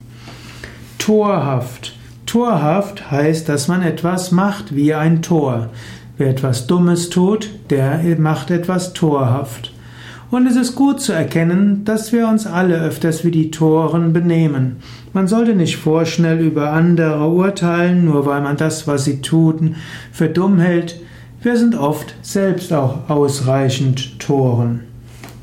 Erfahre einiges zum Thema Torhaft in diesem Spontan-Vortragsaudio.
Dieser Audio Podcast über \" Torhaft \" ist die Tonspur eines Videos, zu finden im Youtube Kanal Persönlichkeit, Ethik und Umgang mit Schattenseiten.